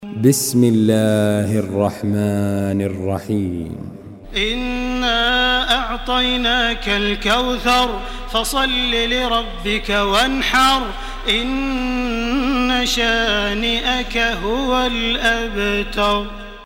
Surah Al-Kawthar MP3 by Makkah Taraweeh 1429 in Hafs An Asim narration.
Murattal Hafs An Asim